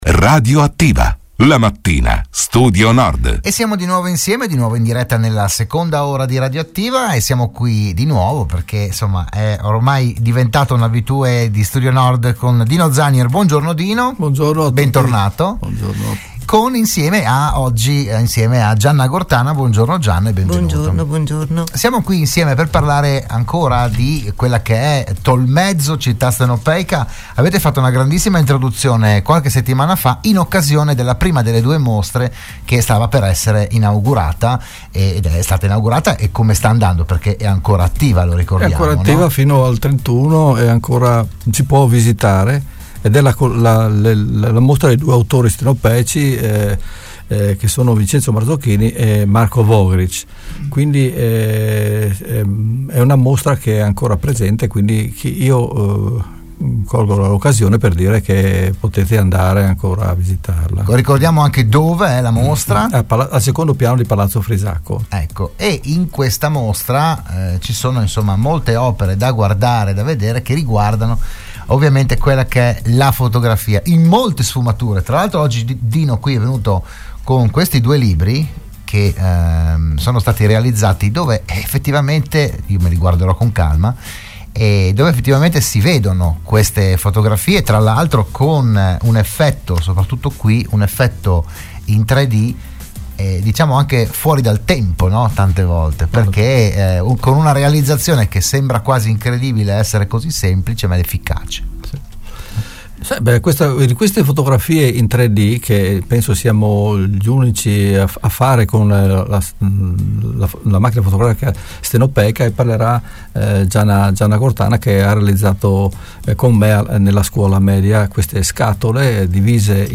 L'audio e il video dell'intervento a Radio Studio Nord